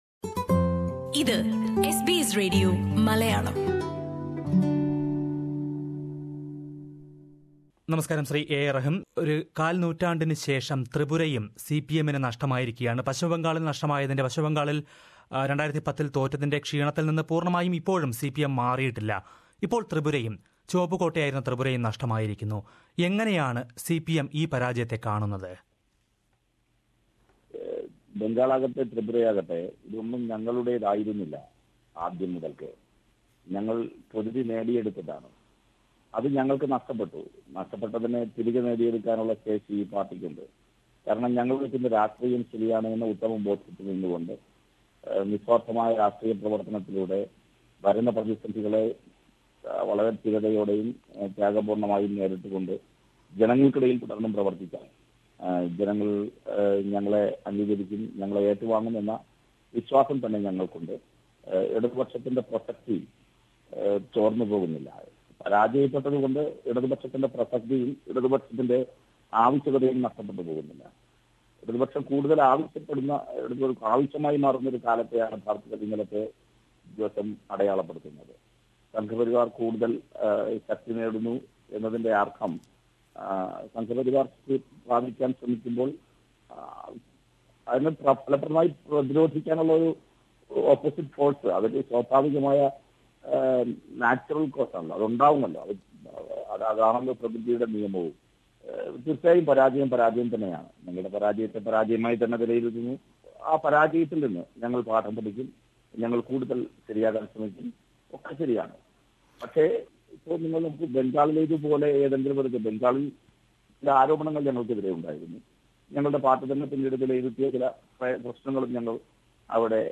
What did CPI(M) learn from the results in Tripura? CPI(M) leader A A Rahim talks to SBS Malayalam Radio.